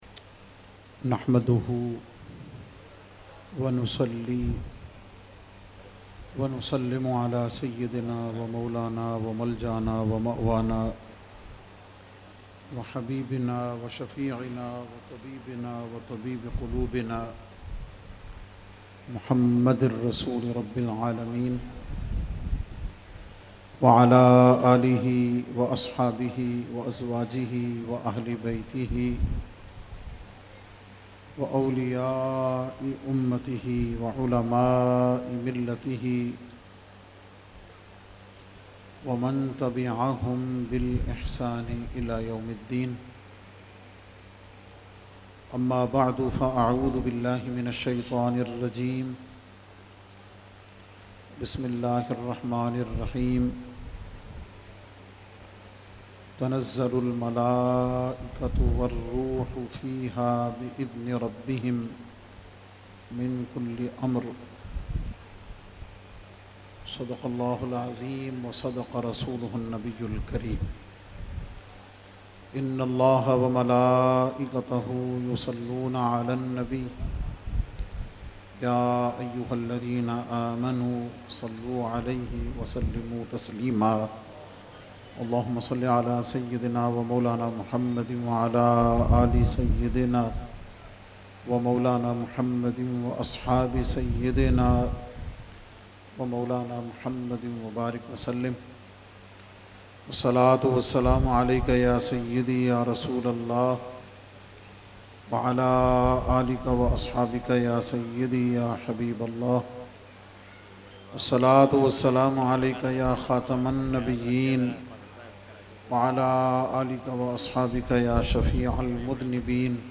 Category : Speech | Language : UrduEvent : Urs Makhdoome Samnani 2020